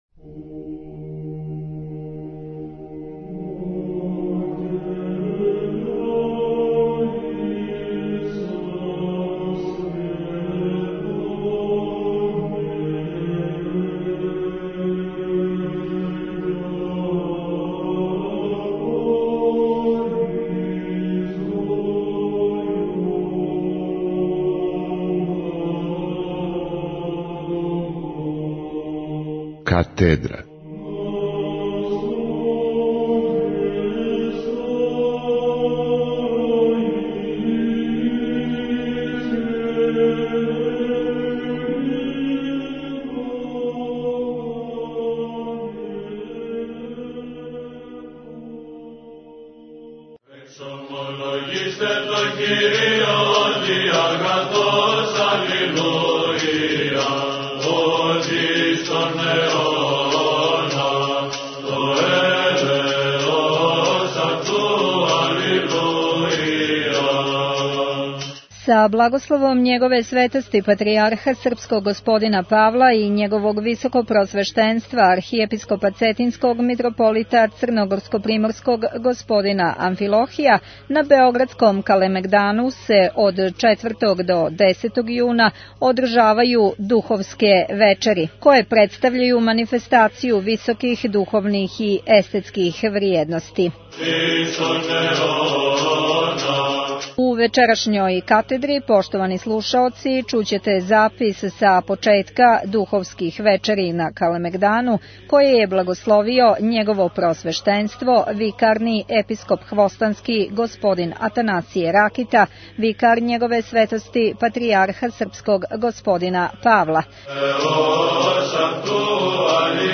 Духовске вечери које су од четвртка 4. јуна почеле на београдском Калемегдану, на платоу испред Цркве Свете Петке и у Цркви Ружици, представљају манифестацију високих духовних и естетских вриједности. Сваке вечери до 10. јуна биће приређивани програми, када ће бесједити неки од Епископа.